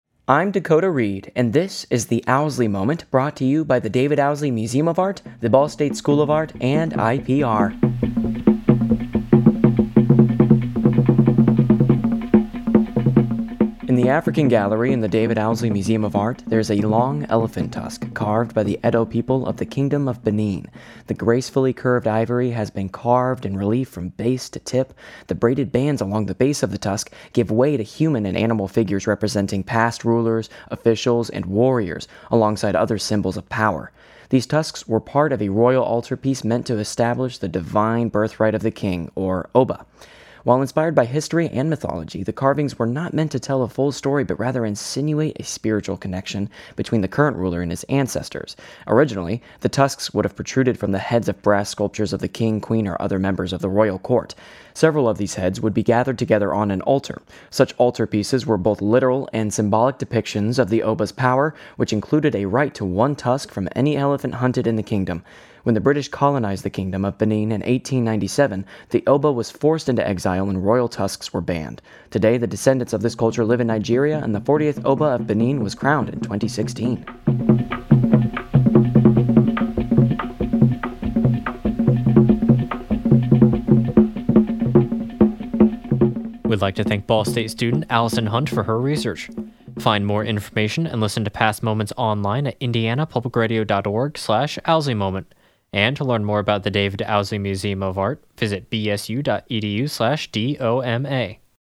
Music used in this episode:
Yewe Cult Dance
Composed and Performed by Ewe Musicians